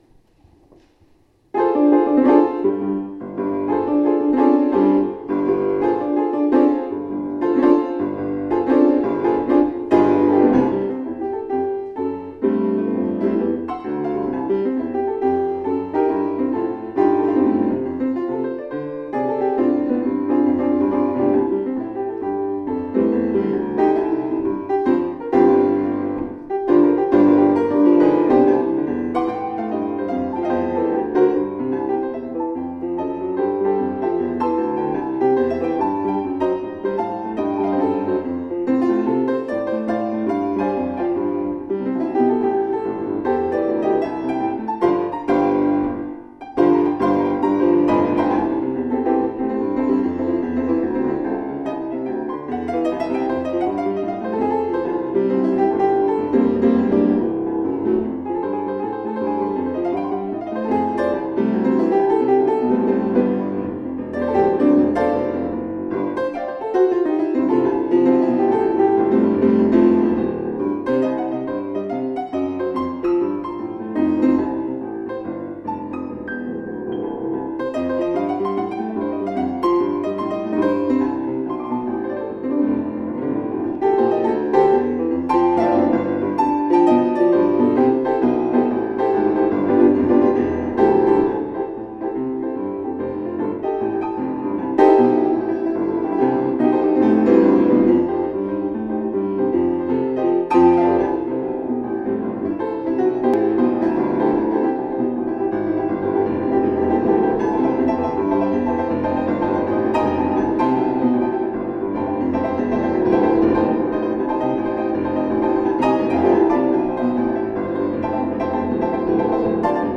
piano pieces from services in my files now.
this pandemic & 2. a classical pianist vs jazz?!)